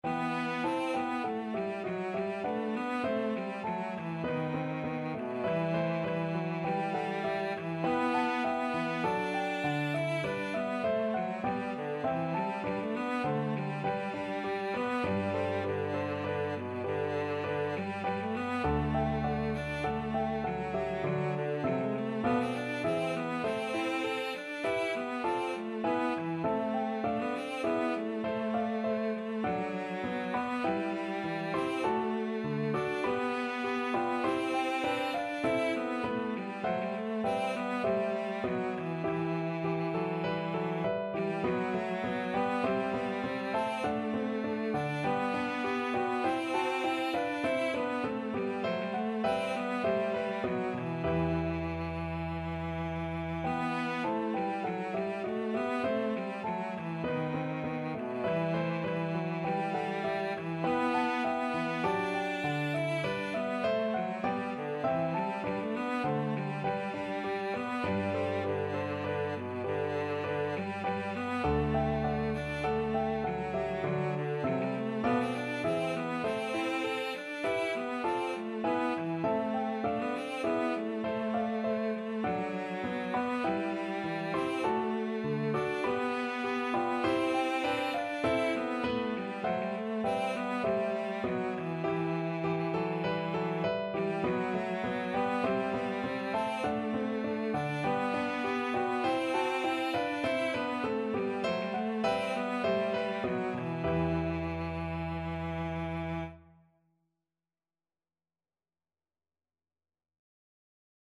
Cello
E minor (Sounding Pitch) (View more E minor Music for Cello )
Classical (View more Classical Cello Music)